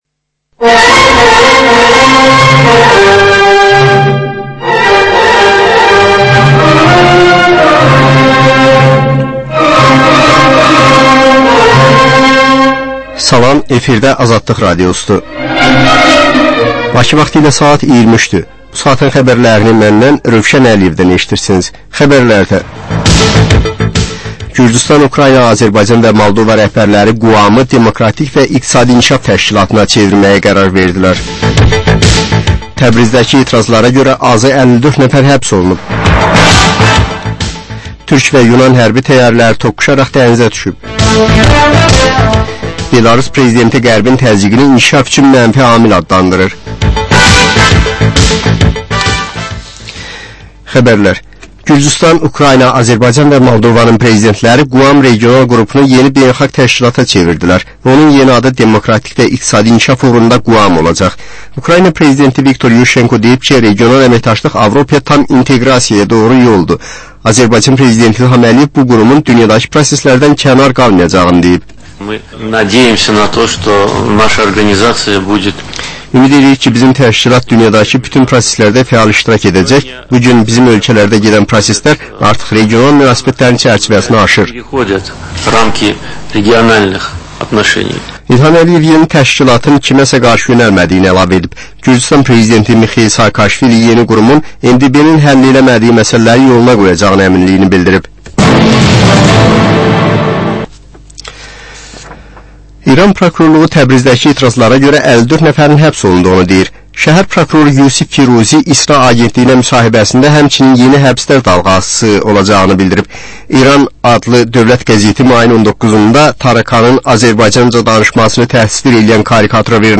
Xəbərlər, reportajlar, müsahibələr.